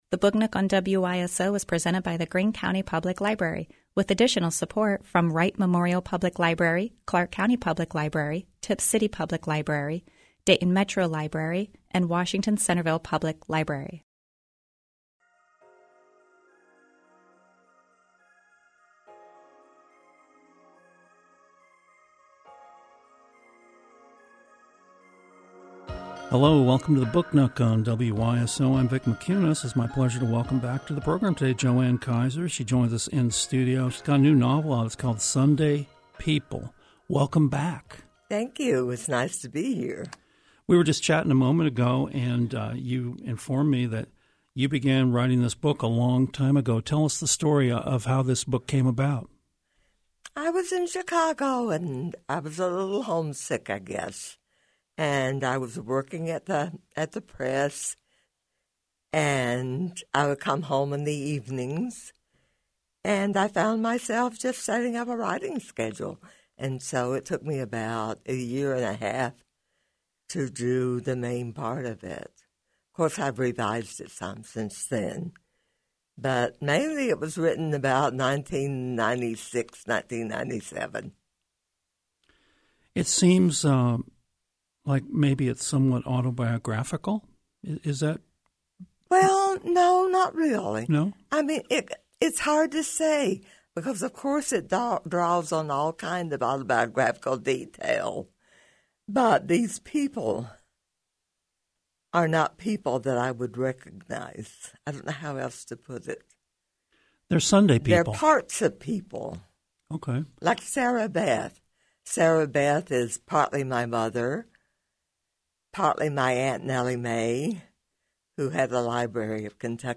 Best of Book Nook: Some Favorite Interviews from 2024 – Book Nook – Podcast – Podtail